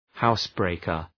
{‘haʋs,breıkər}